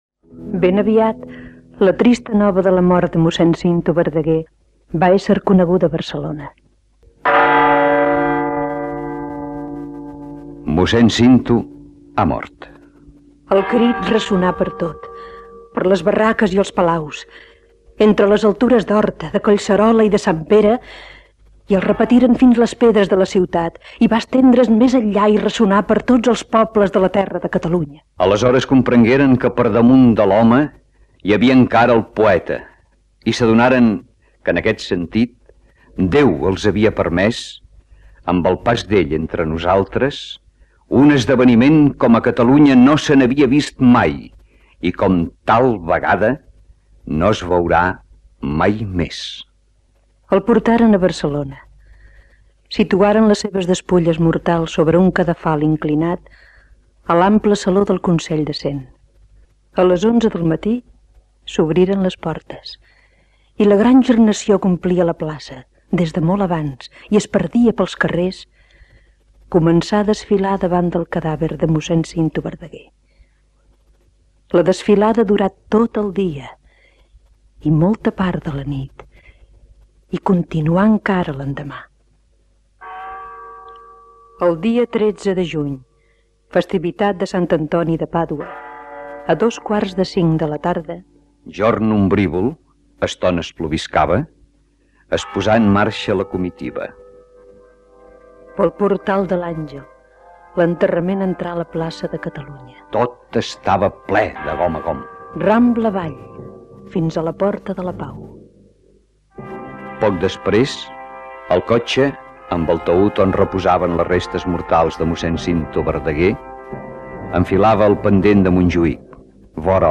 Programa especial.